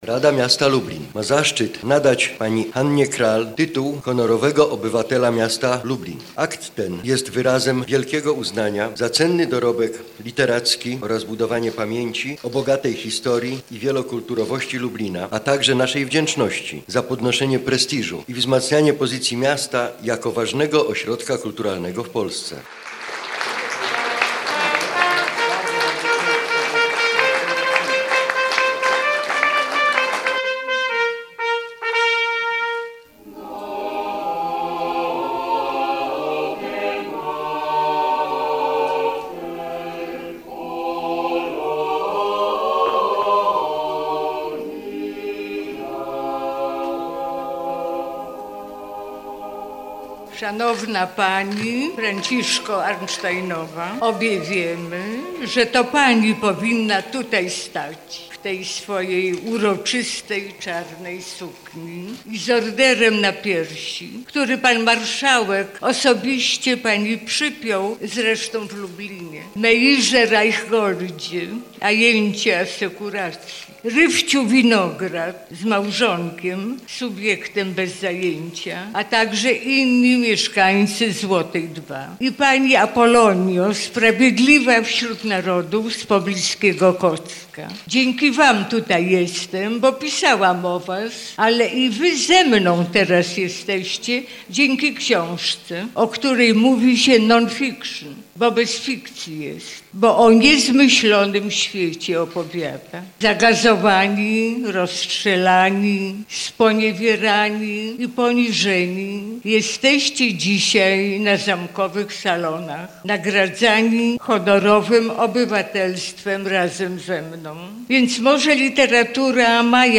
Hanna Krall została Honorową Obywatelką Miasta Lublin. Uroczystość nadania aktu tego wyróżnienia odbyła się w piątek (30.05) w Muzeum Narodowym w Lublinie.
Akt nadania Honorowego Obywatelstwa Miasta Lublin Hannie Krall odczytał Przewodniczący Rady Miasta Lublin.